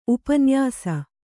♪ upanyāsa